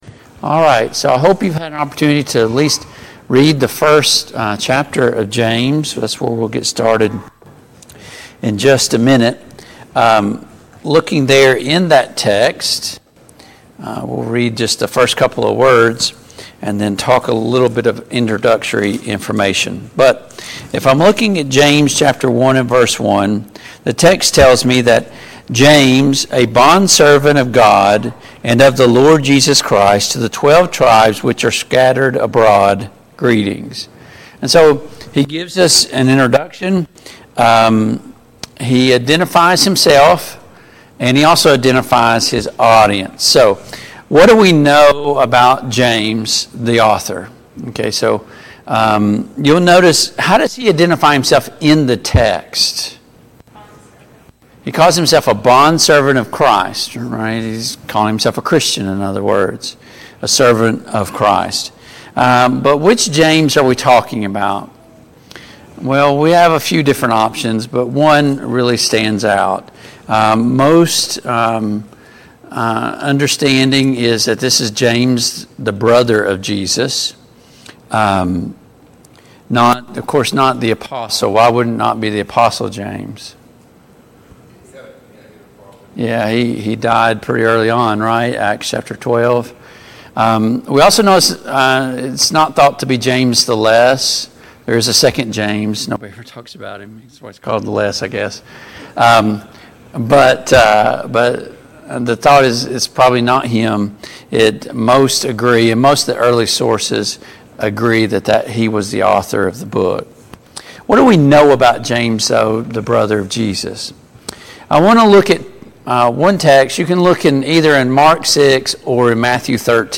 James 1:1-2 Service Type: Family Bible Hour Topics: Introduction to James « Can we watch and pray with Him for one hour?